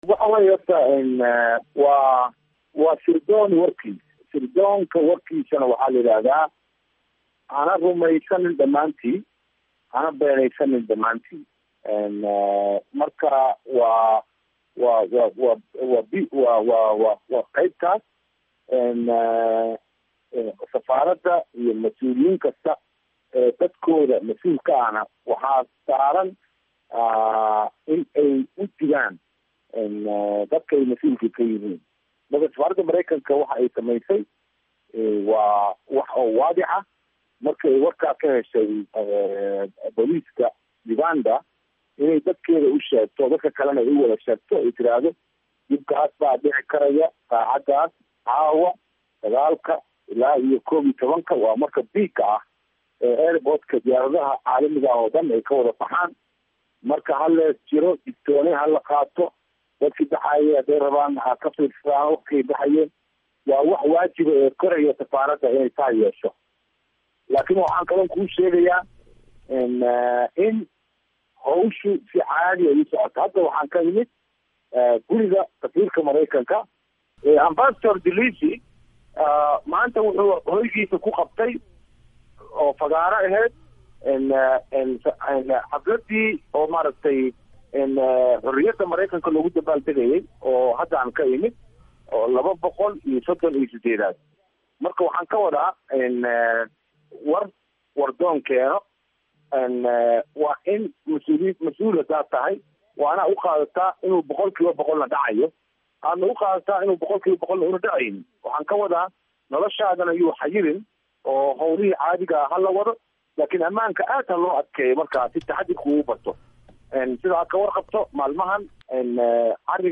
Waraysiga digniinta weerarka Uganda